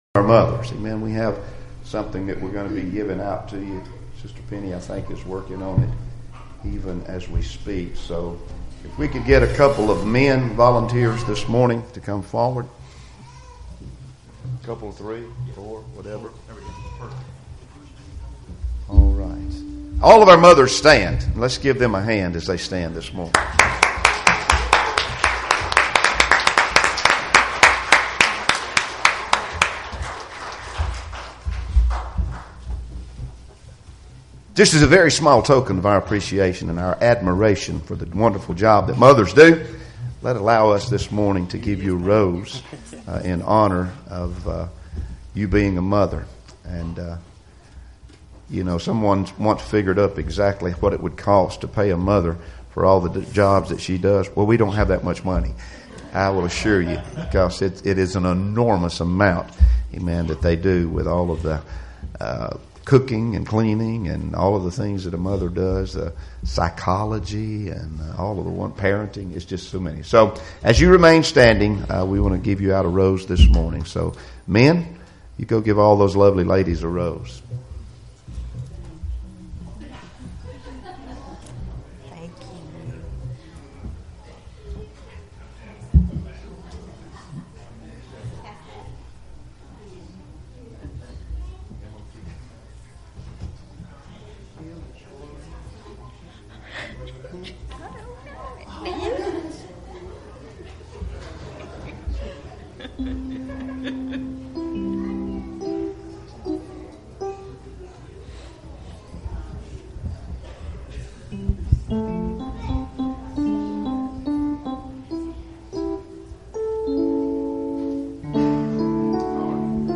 X 2 Kings 4:1-7 Service Type: Sunday Morning Services Topics